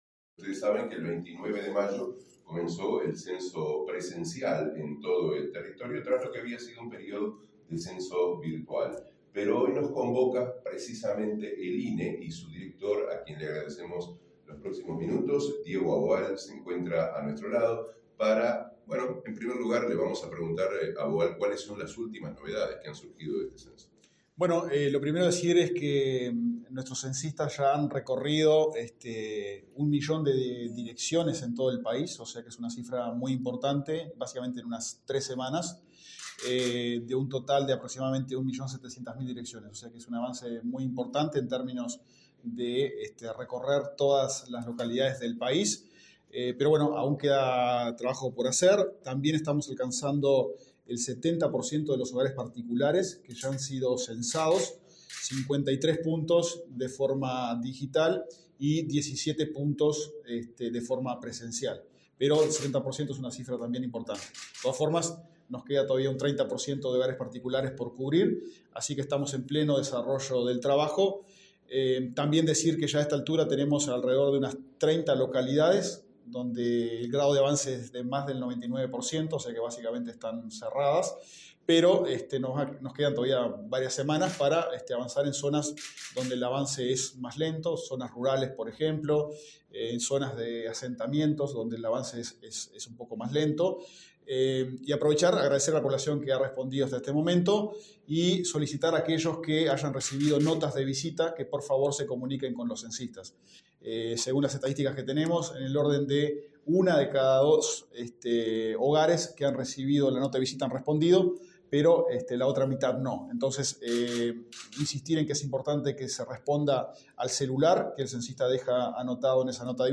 Conferencia del director del INE, Diego Aboal
El director del Instituto Nacional de Estadística (INE), Diego Aboal, se expresó en una conferencia de prensa en la Torre Ejecutiva el jueves 22,